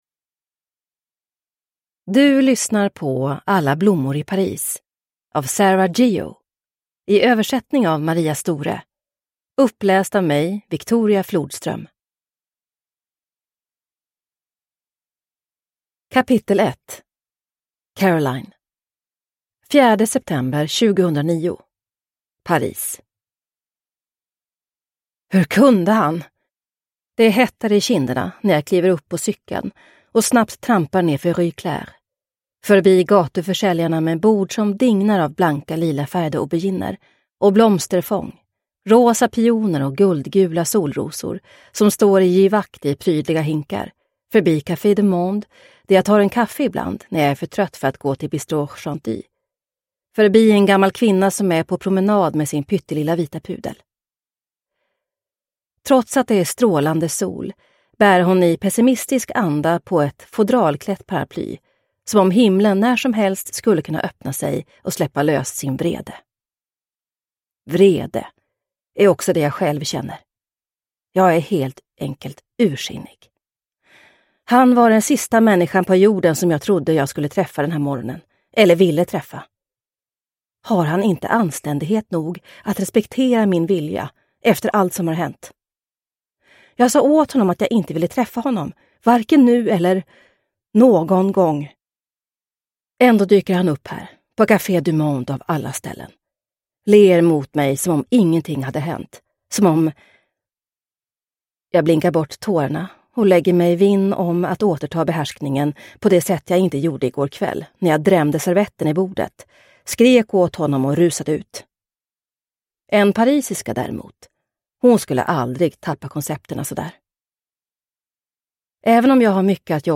Alla blommor i Paris – Ljudbok – Laddas ner